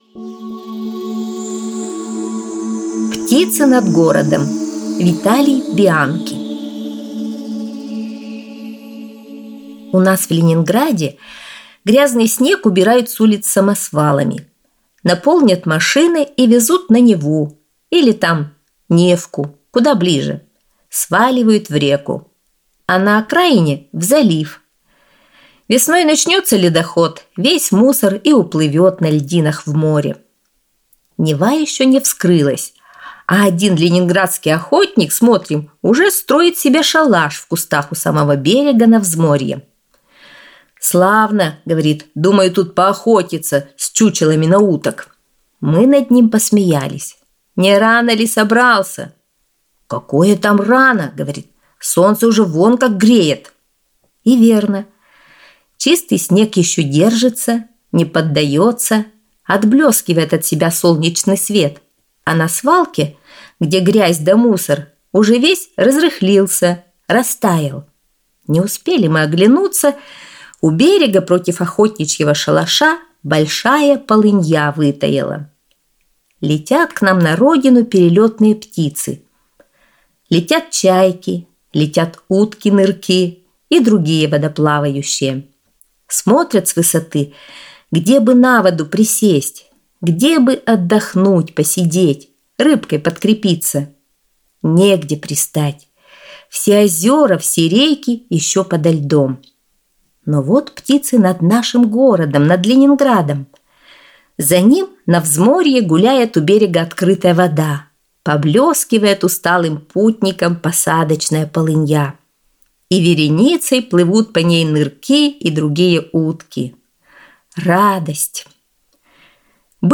Аудиорассказ «Птицы над городом»